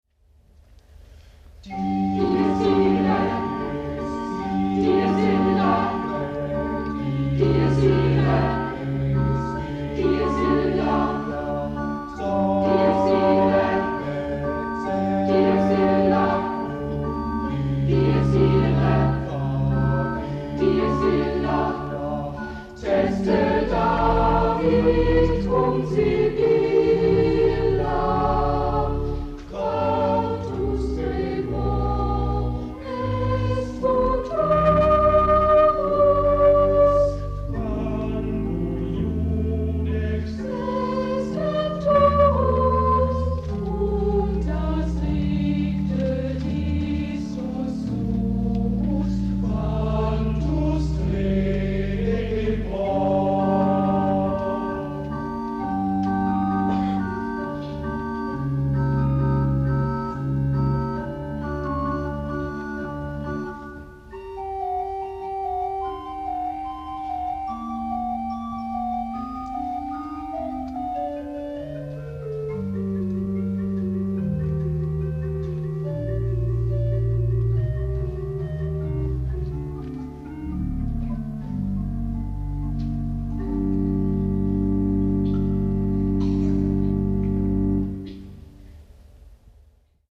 die sätze für ein requiem für chor und orgel
in der laboer anker-gottes-kirche